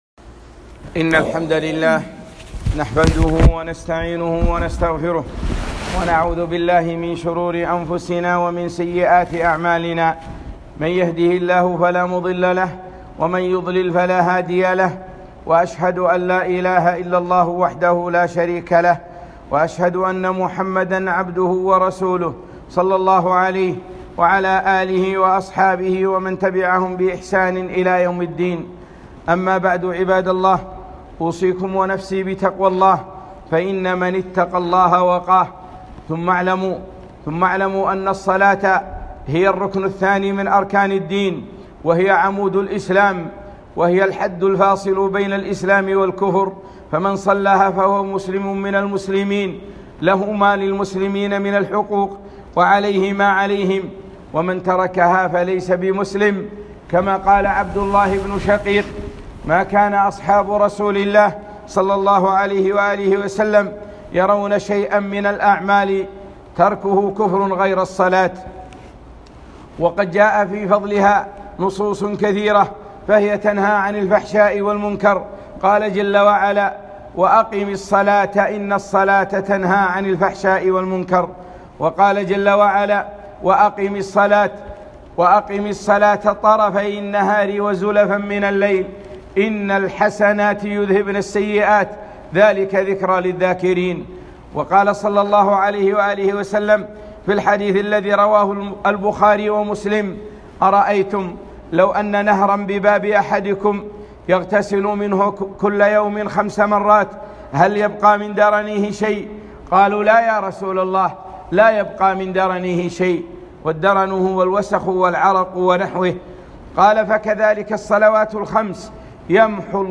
خطبة - الصلاة و أهميتها - دروس الكويت